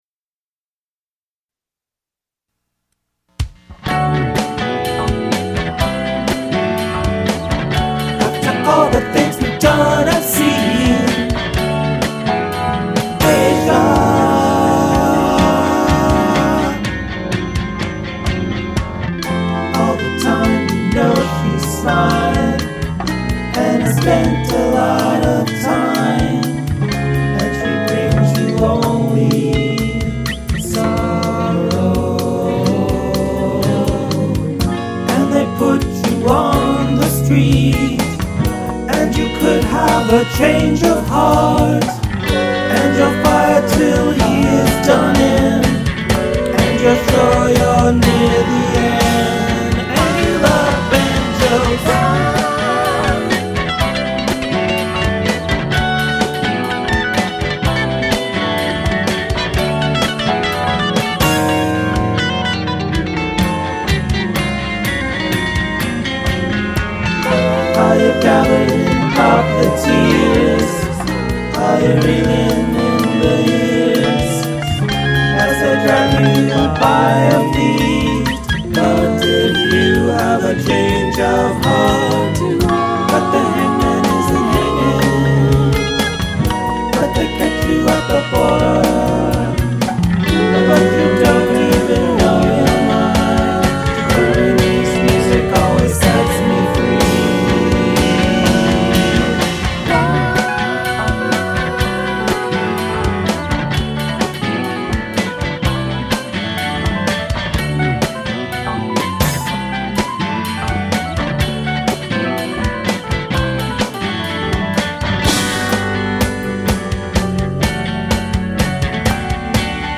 and I. We each chose 4 bands to imitate, generally doing instrumental tracks and leaving vocals plus this and that for the other guy to complete.